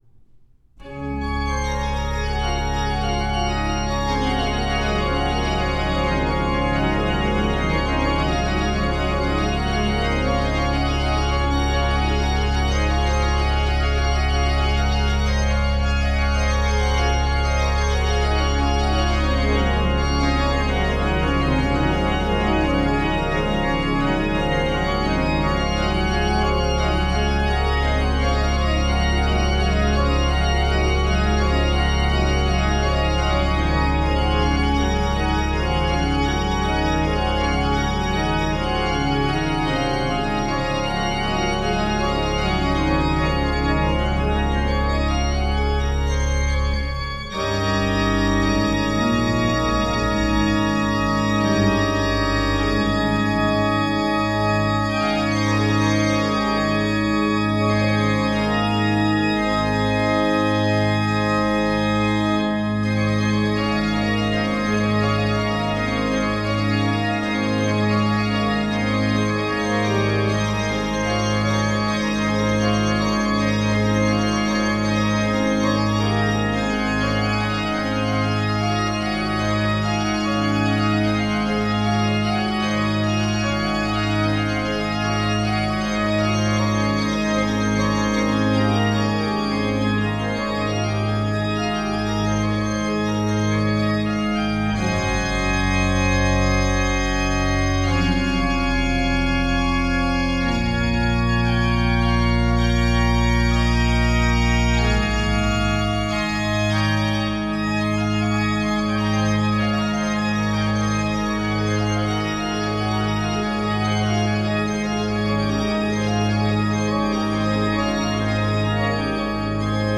Auf restaurierten Orgeln (z.B.
Egedacher Orgel in Vornbach von 1732) aus dem 17. und 18. Jahrhundert erklingen Orgelwerke „authentisch“.
Vornbach 2012 Pachelbel Präludium C Dur u. g moll.mp3